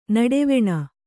♪ naḍeveṇa